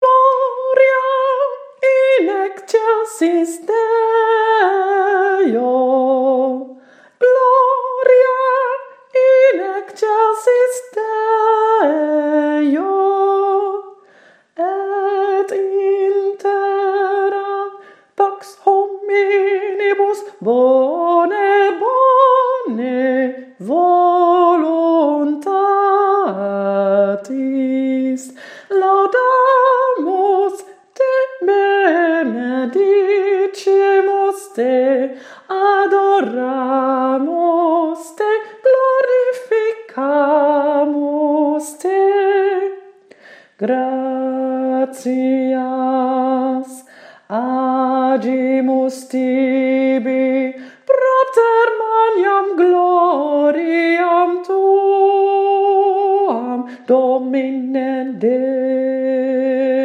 Messe Brève Gounod no 7. In C
Link naar oefenmateriaal Gloria Bas snel
Gloria mt1-83 snel.mp3